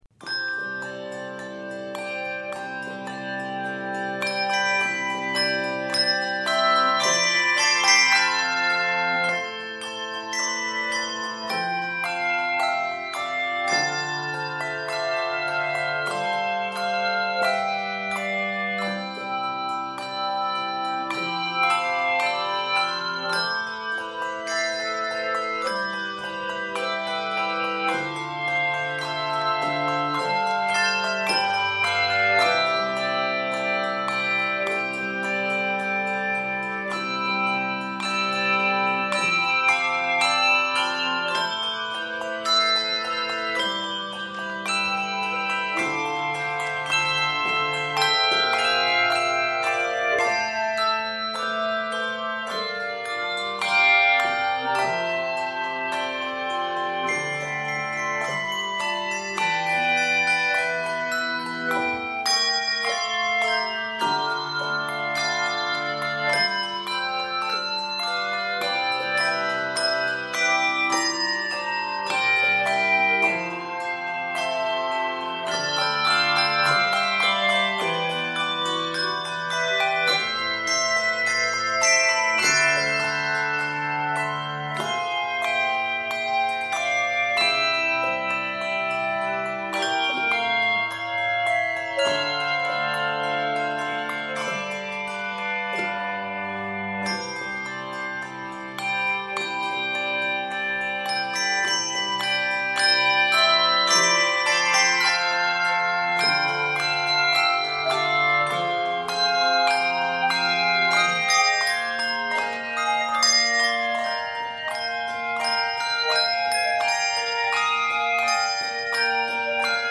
offers a flowing, peaceful presentation